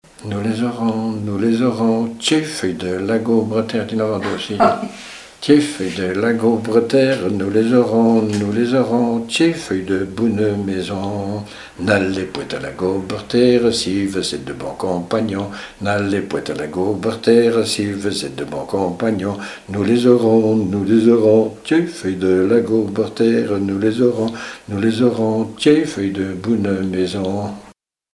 Couplets à danser
Répertoire de chants brefs pour la danse
Pièce musicale inédite